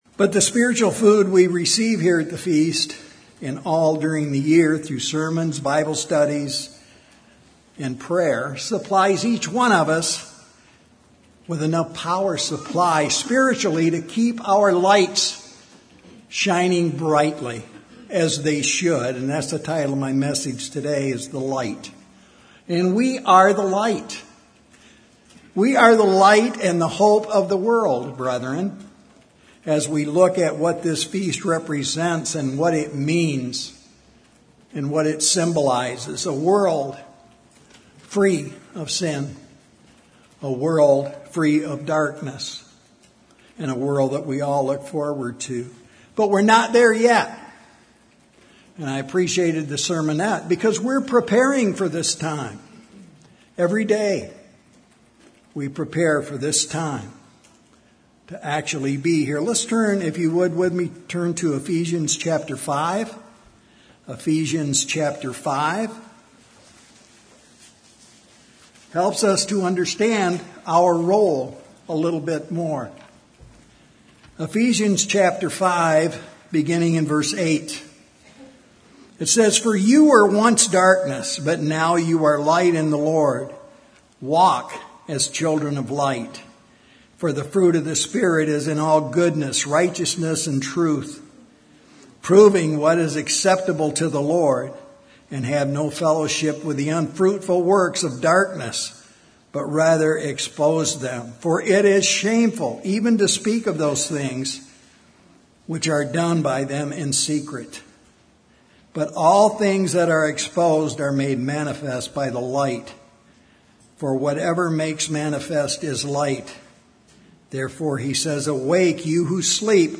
This sermon was given at the Cincinnati, Ohio 2021 Feast site.